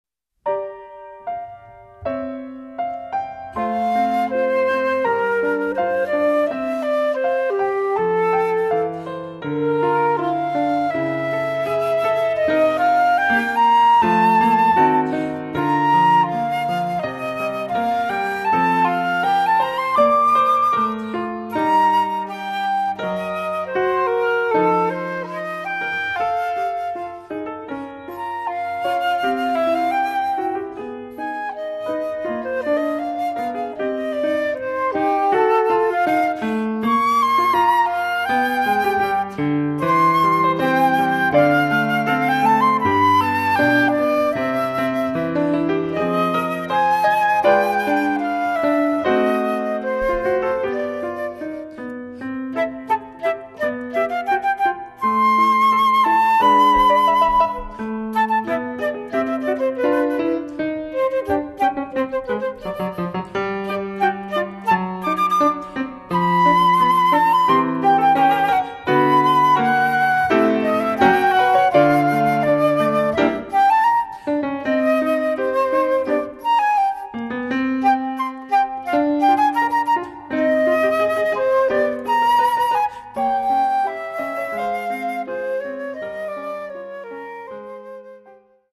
Flute
Piano